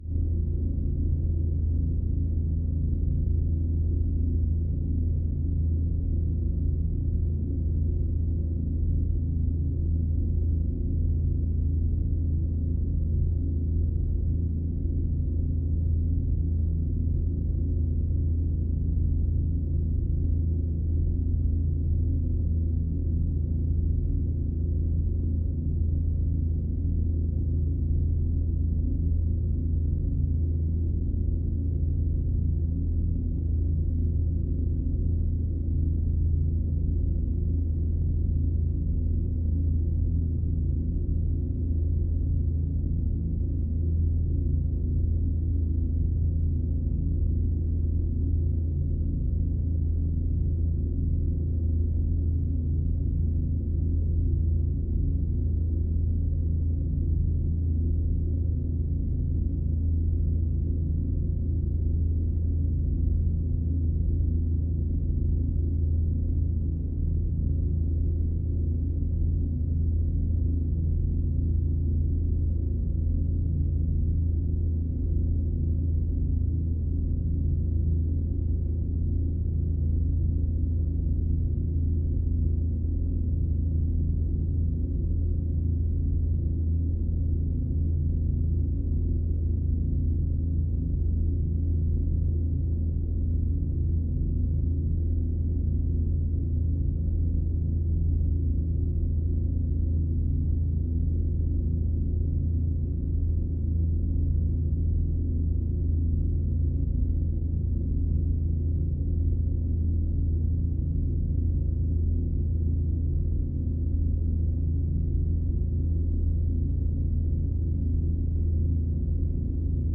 DeltaVR/Scifi, Energy, UFO Force Field, Low, Static Roar SND73775 1.wav at 60b9f21a61e9a486abe491c3d94bef41b06a9b5f